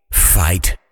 fight.ogg